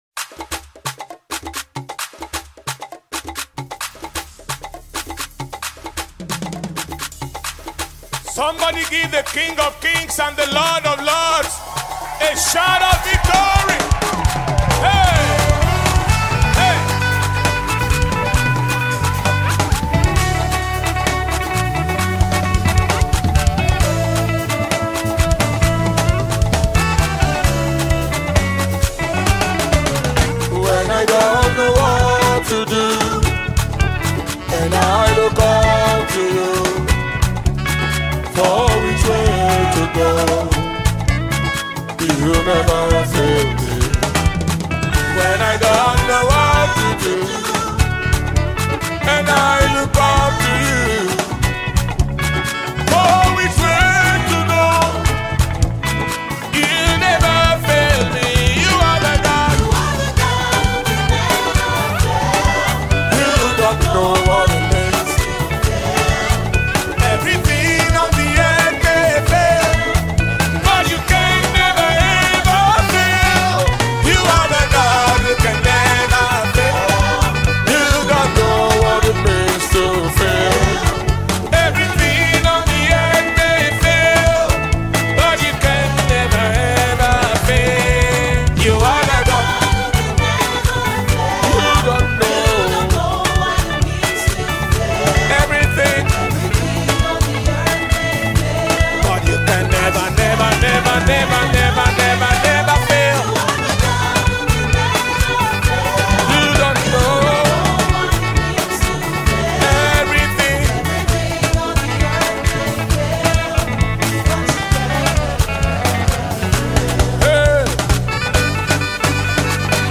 live performance single of praise